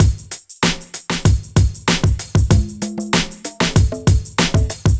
Track 14 - Drum Break 05.wav